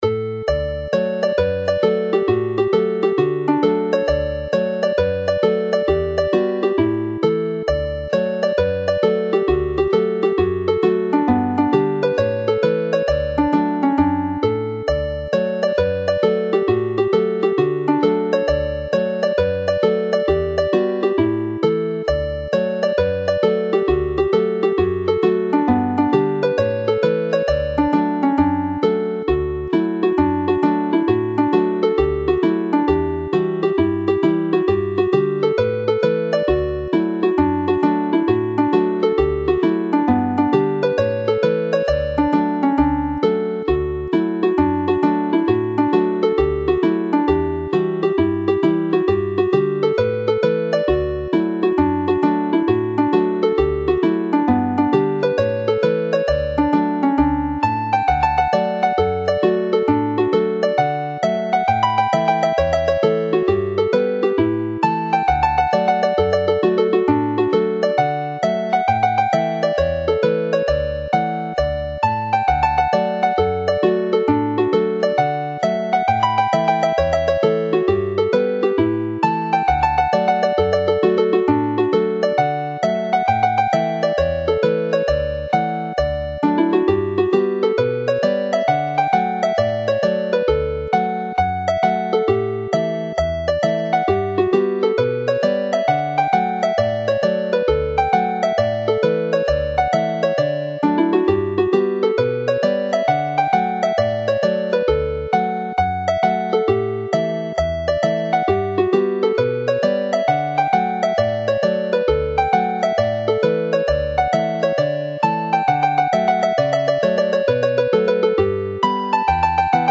Hornpipes are usually presented in straight 4/4 time in scores which if played as written become a reel, but are normally played with a skip which is more accurately shown by a 12/8 signature, as illustrated by the second score of the Belfast Hornpipe which also illustrates how triplets and general ornamentation fit so well into the skip timing of a hornpipe.
The sound files all play as hornpipes with the 12/8 timing opening the way for triplets to be used to elaborate on the melody.